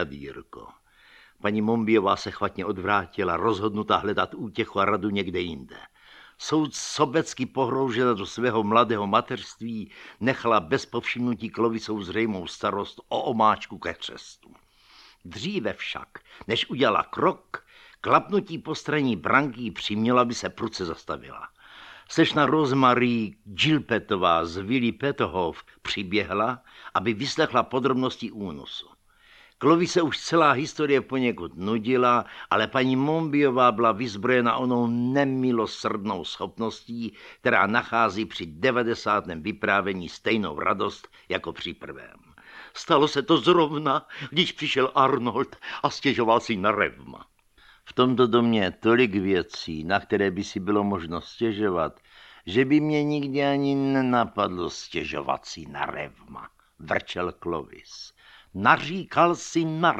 Audiobook
Read: Oldřich Nový